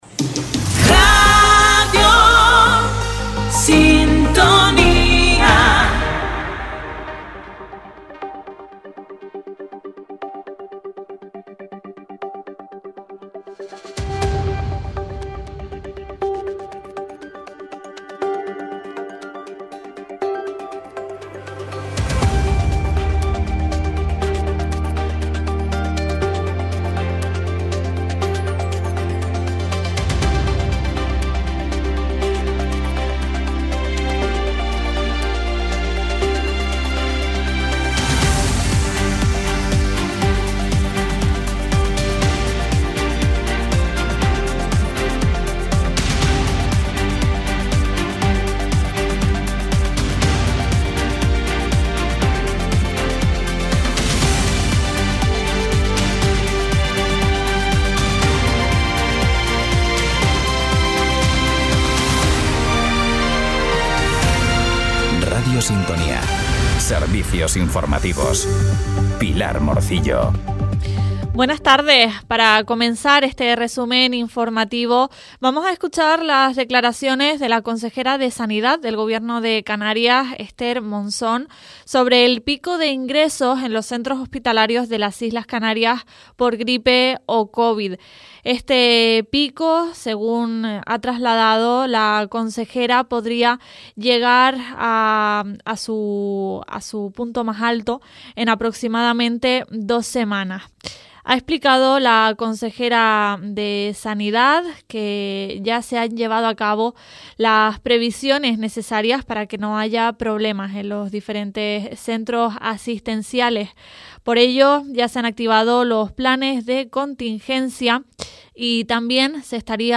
Informativos en Radio Sintonía -16.01.25 - Radio Sintonía